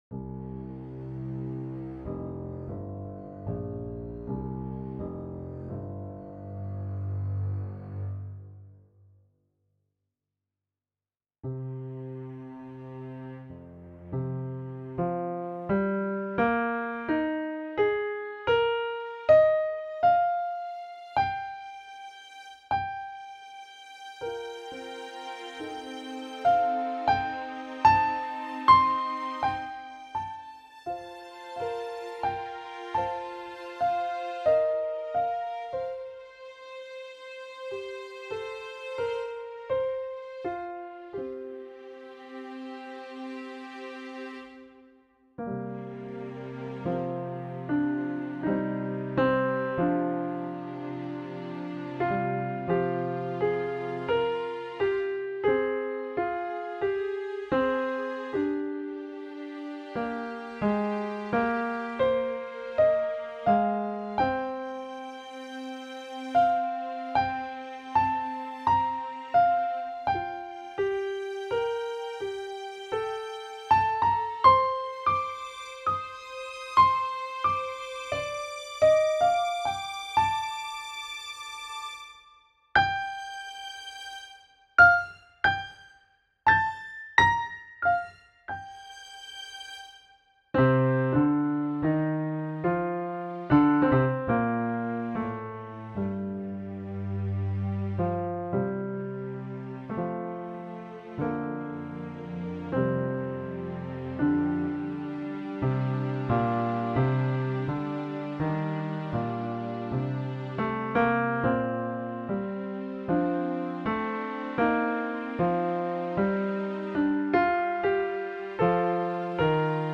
p-improvised-themevariations-mix.mp3